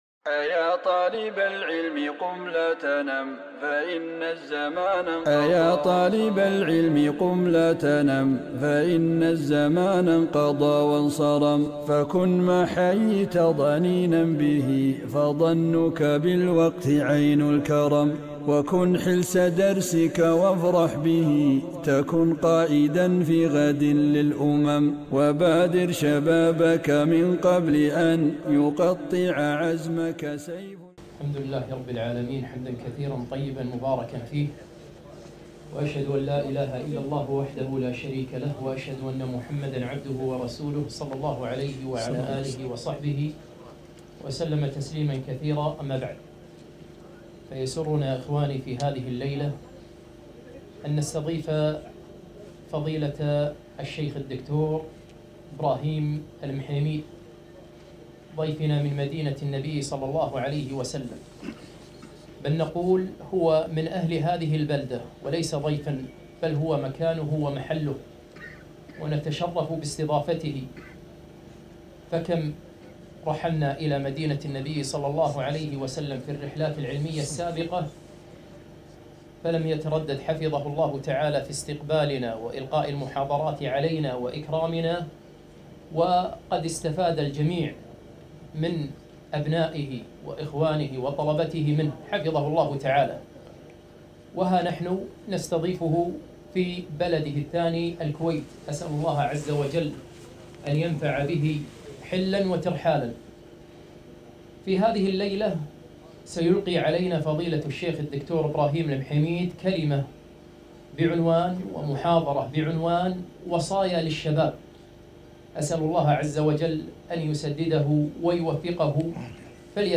محاضرة - وصايا للشباب